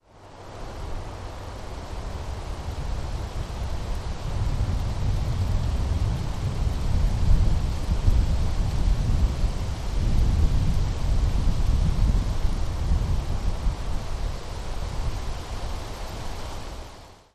Spring trip to Yosemite Valley
Wind in the trees by the Merced
016_wind_in_trees_by_Merced.mp3